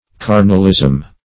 Carnalism \Car"nal*ism\, n.